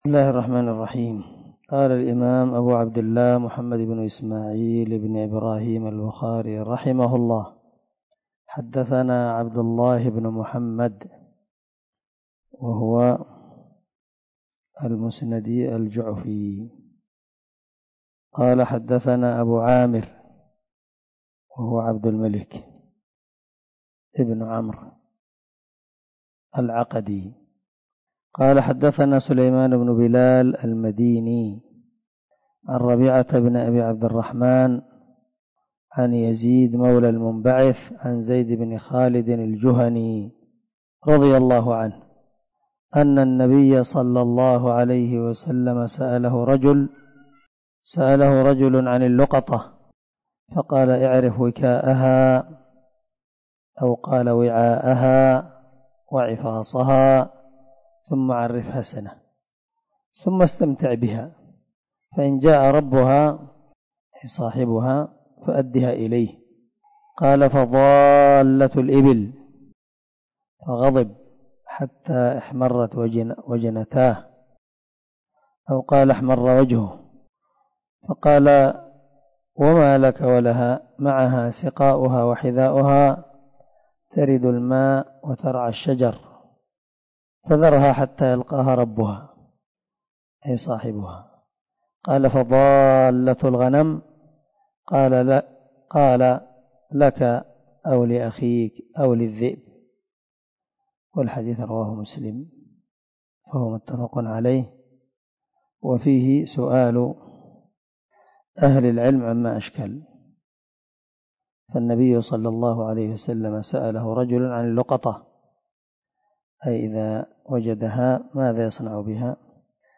089الدرس 34 من شرح كتاب العلم حديث رقم ( 91 ) من صحيح البخاري
دار الحديث- المَحاوِلة- الصبيحة.